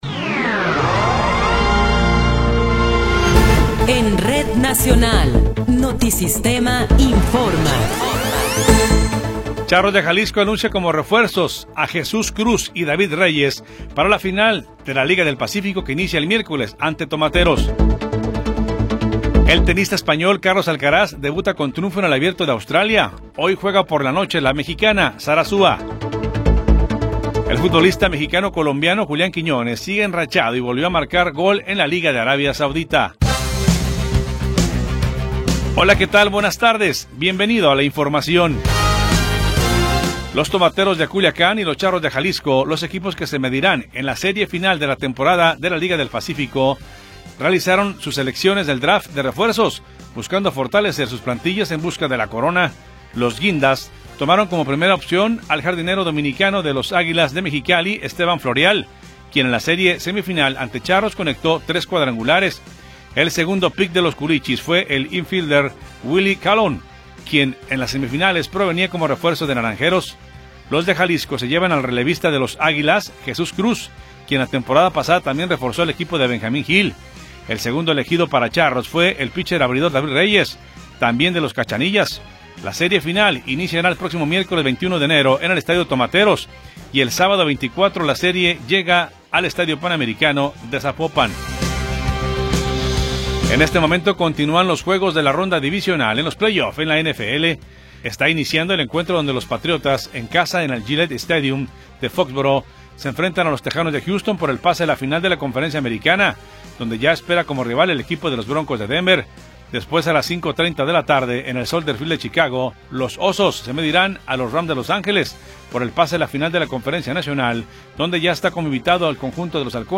Noticiero 14 hrs. – 18 de Enero de 2026